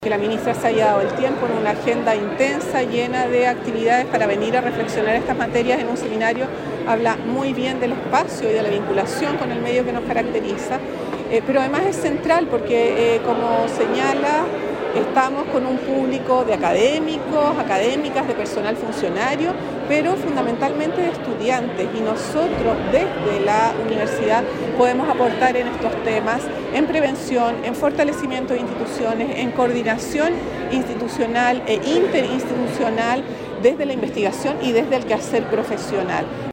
En medio de la apretada agenda de su visita al Biobío, la ministra Carolina Tohá llegó hasta la Universidad de Concepción para participar del seminario “Futuro y seguridad pública en Chile”, organizado por la Facultad de Ciencias Jurídicas y Sociales, oportunidad en la que pudo exponer sobre la situación del país en seguridad y los desafíos y avances del Gobierno en dicha materia ante un público compuesto principalmente por académicos, funcionarios y estudiantes.